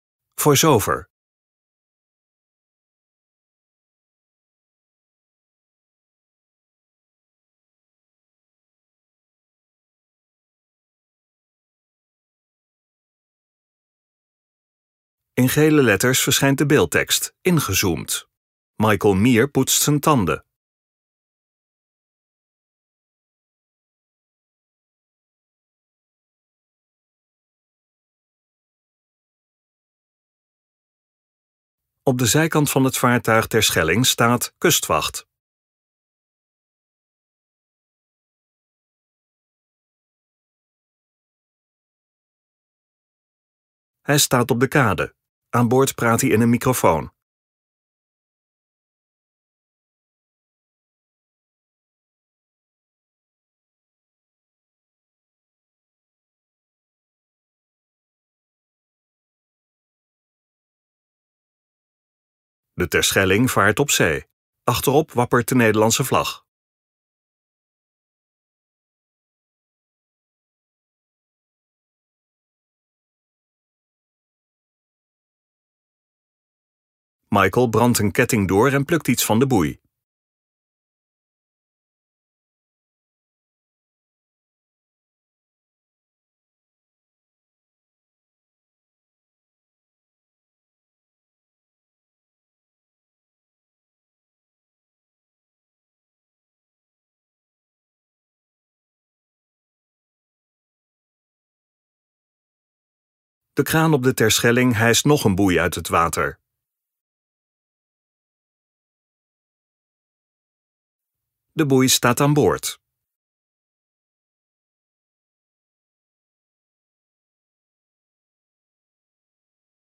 Vlog 12 augustus 2022